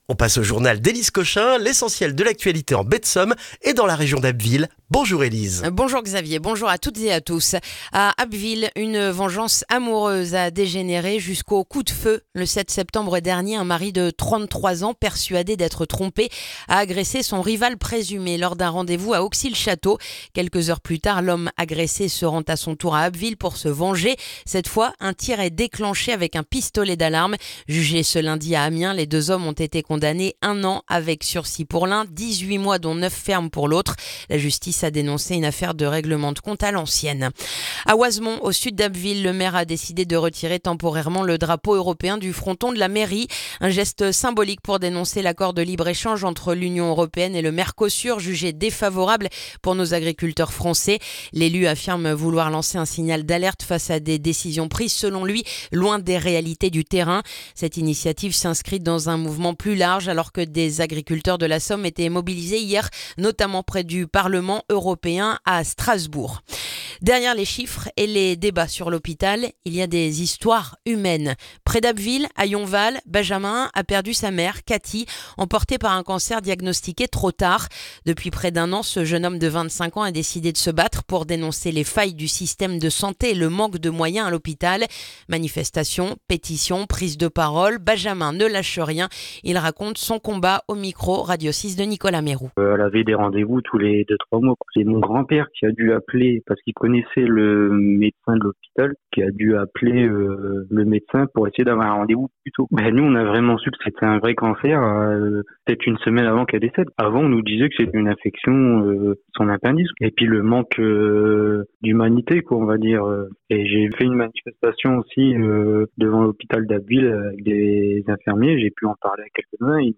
Le journal du mercredi 21 janvier en Baie de Somme et dans la région d'Abbeville